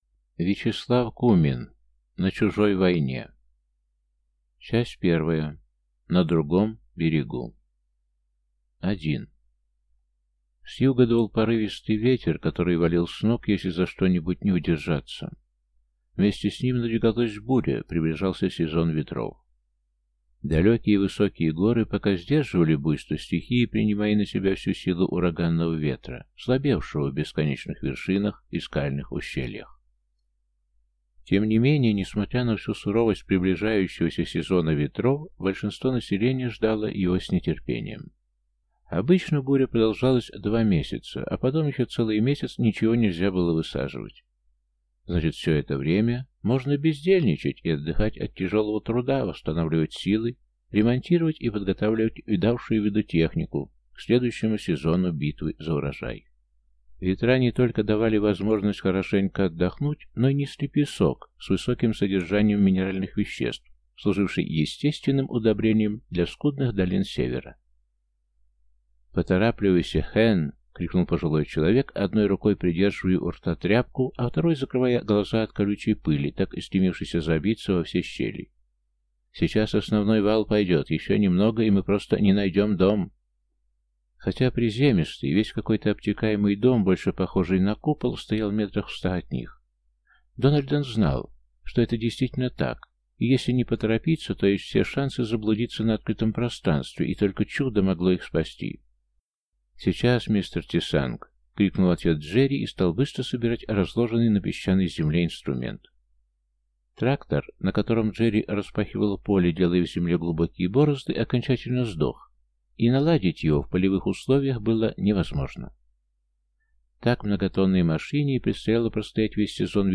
Aудиокнига На чужой войне